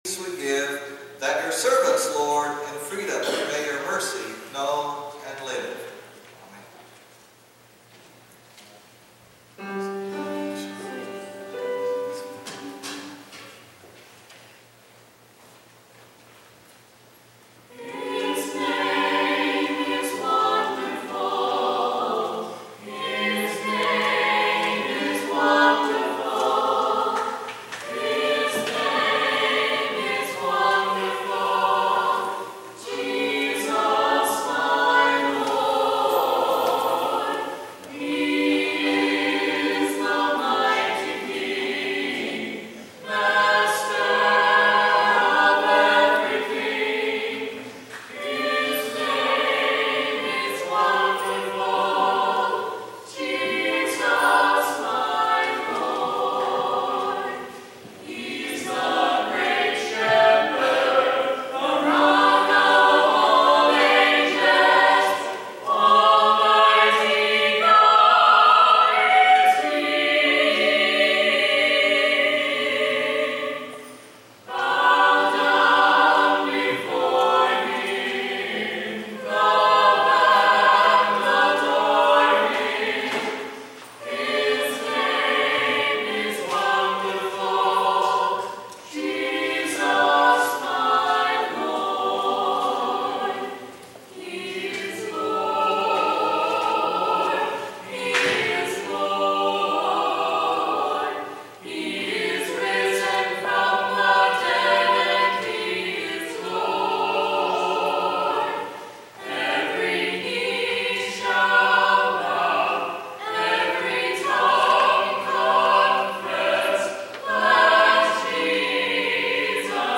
Today’s Offertory was a quartet — a capella